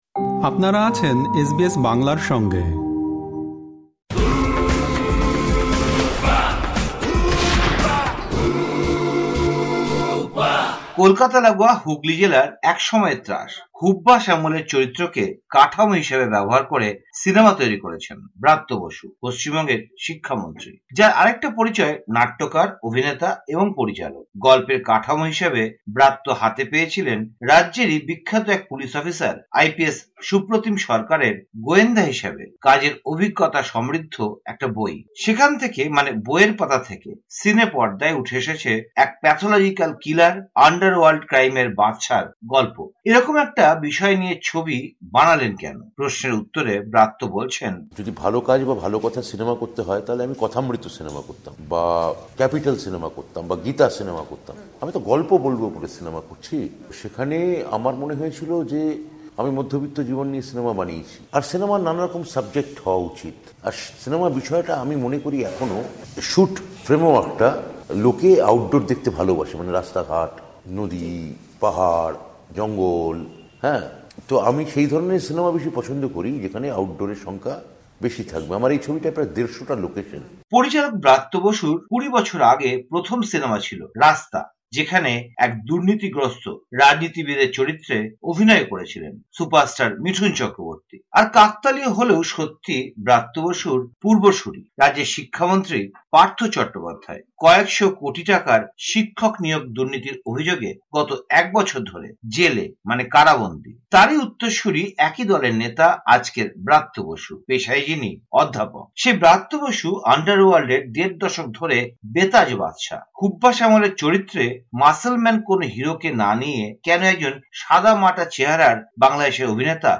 হুব্বা নিয়ে যা বললেন পরিচালক ব্রাত্য বসু ও অভিনেতা মোশাররফ করিম